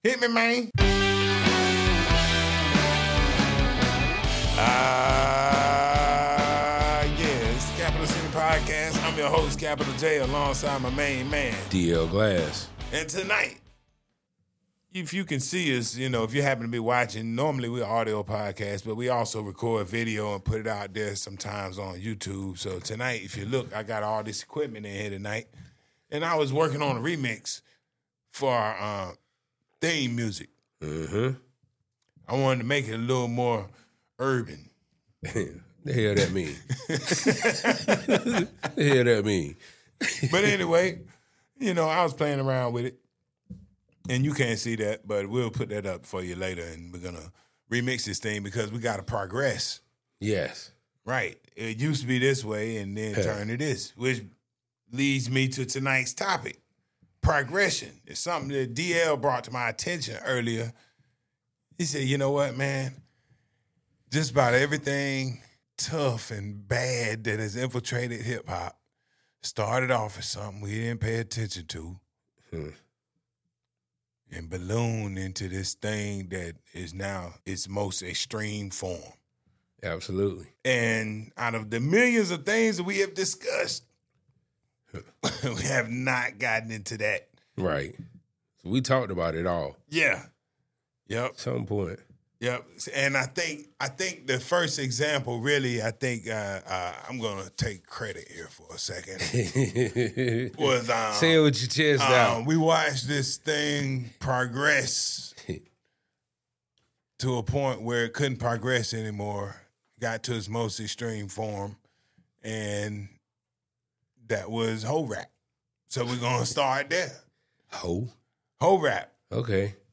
In-depth conversations that discuss the music business and the business of music.